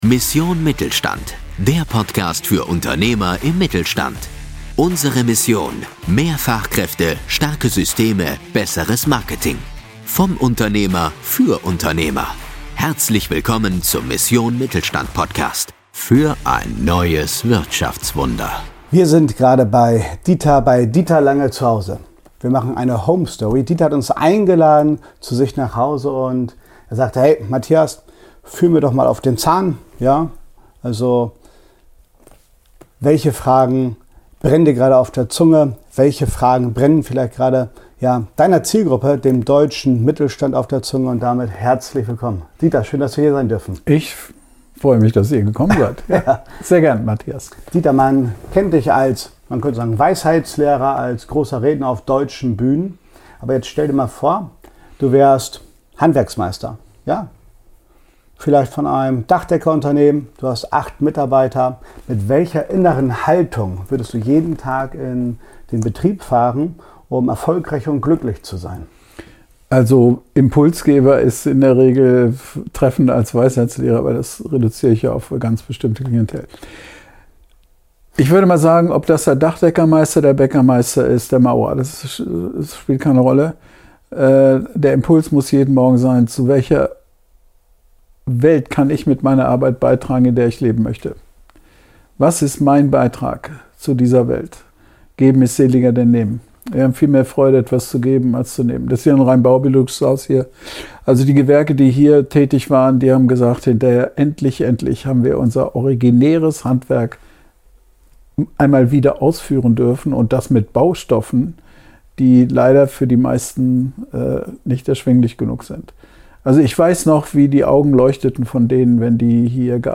Experten-Talk